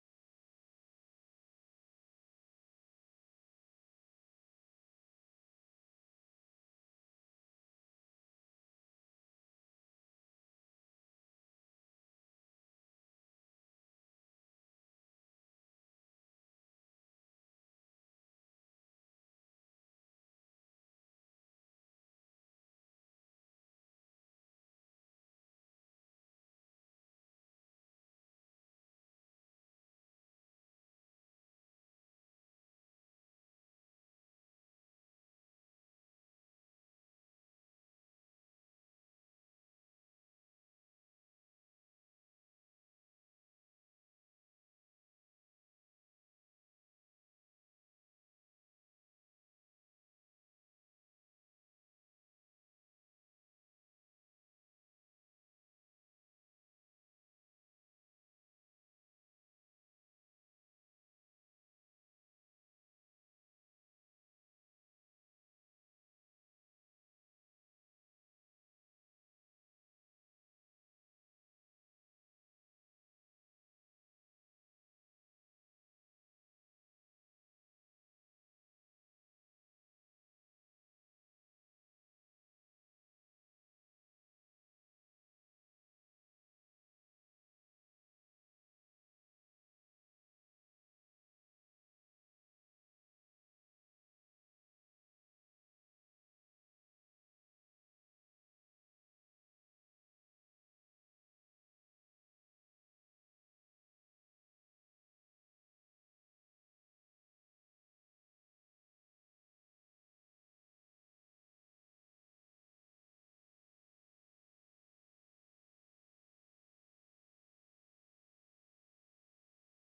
Sunday Service.
Sermons by Versailles Christian Church